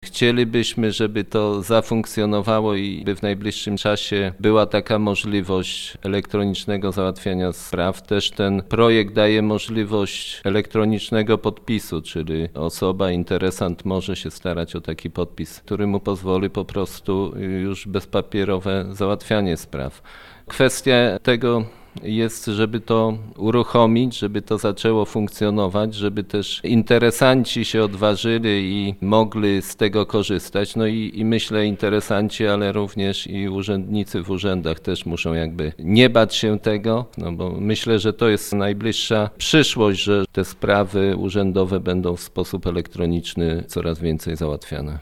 Zdaniem wójta gminy Głusk wdrożenie wszystkich nowych rozwiązań zajmie trochę czasu, ale korzyści z tego wynikające są nie do przecenienia: